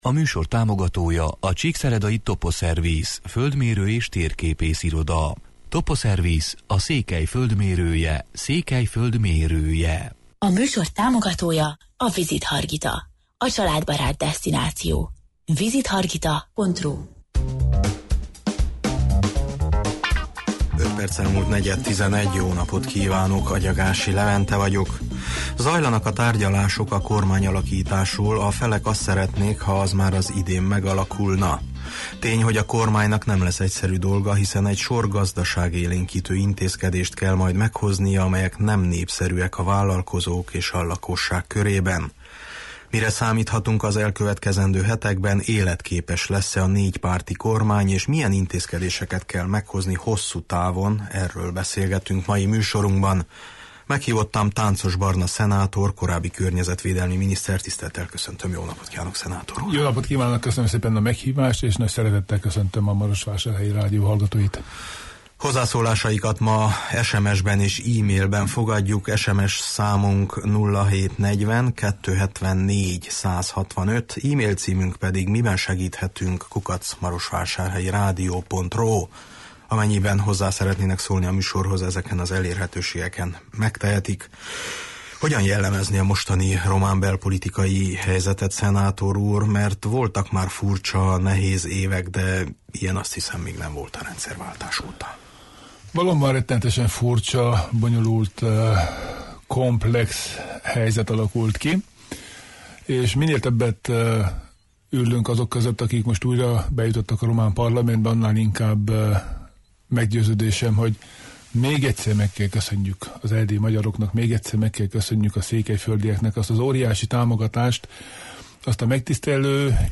Meghívottam Tánczos Barna szenátor, korábbi környezetvédelmi miniszter